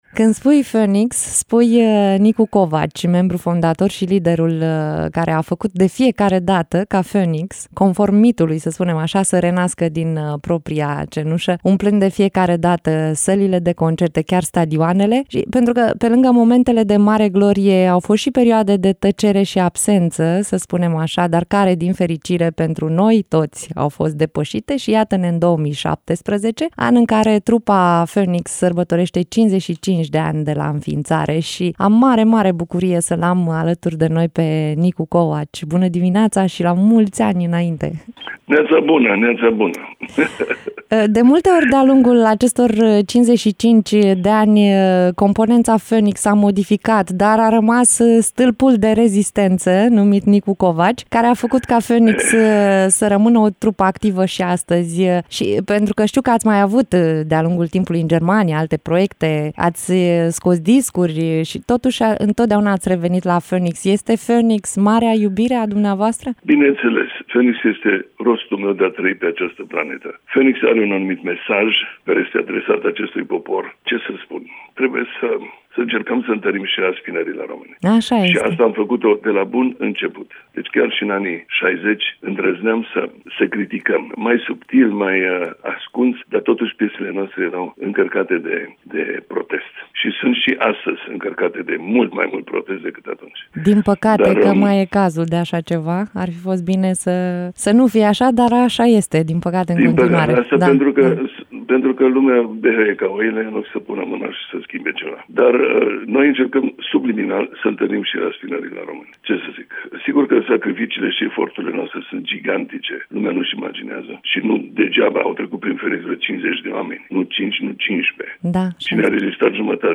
Nicu Covaci, în direct la Radio Iaşi.